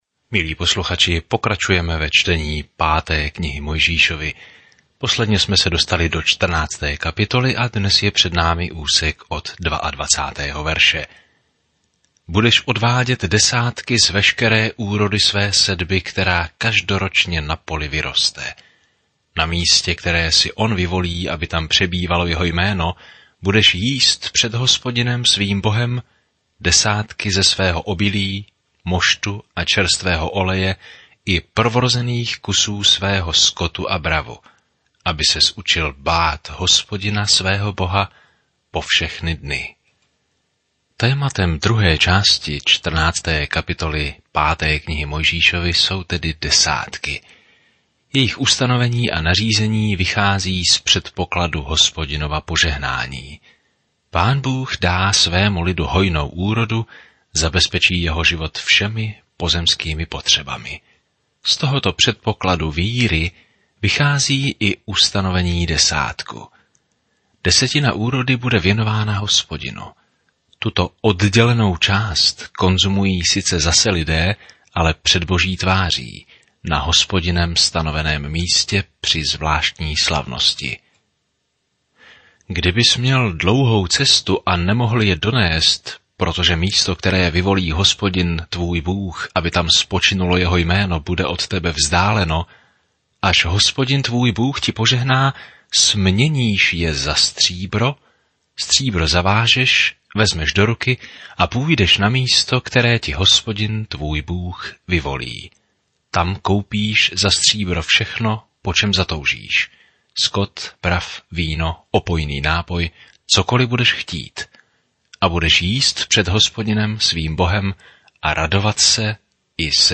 Písmo Deuteronomium 14:22-29 Deuteronomium 15 Deuteronomium 16:1 Den 14 Začít tento plán Den 16 O tomto plánu Deuteronomium shrnuje dobrý Boží zákon a učí, že poslušnost je naší odpovědí na jeho lásku. Denně procházejte Deuteronomium a poslouchejte audiostudii a čtěte vybrané verše z Božího slova.